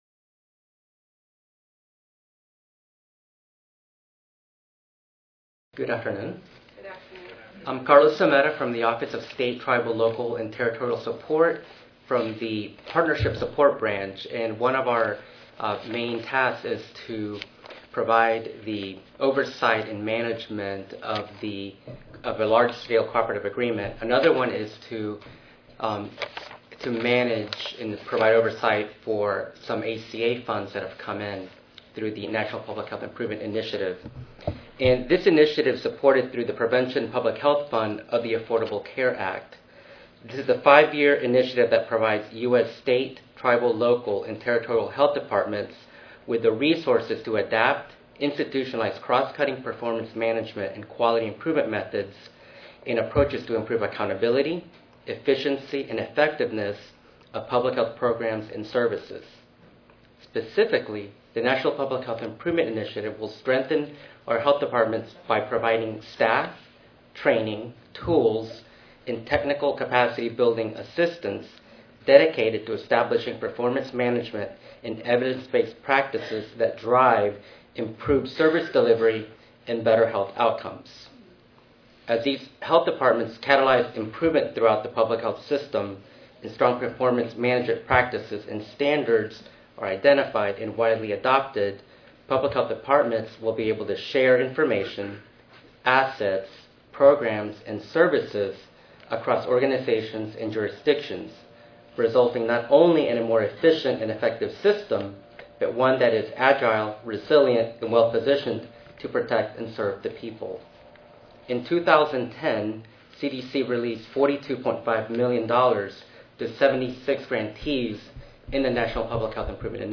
This session will highlight promising practices and early lessons learned from NPHII, as well as present preliminary evaluation results. Panelists will include representatives from health departments and national public health organizations.